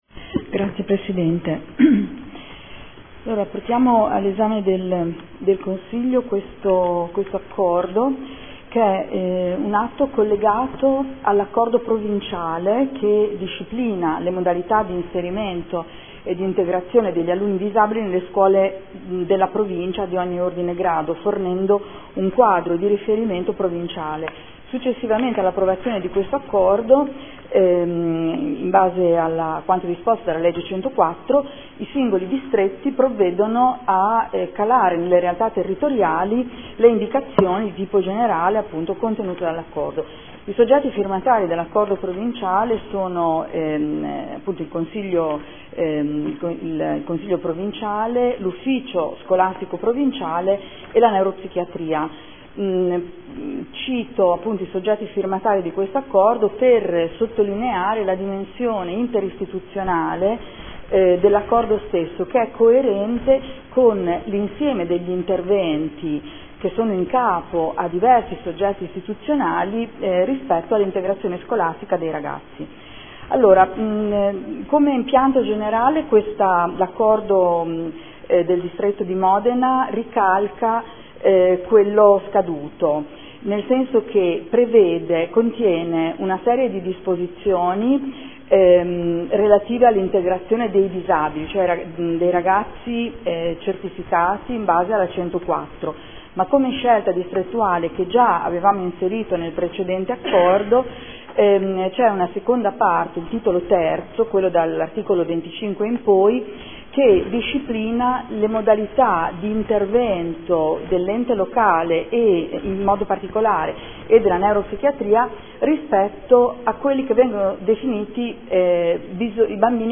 Adriana Querzè — Sito Audio Consiglio Comunale
Seduta del 20/03/2014 Approvazione dell’accordo di distretto inerente l’integrazione scolastica degli alunni disabili nei nidi e scuole di ogni ordine e grado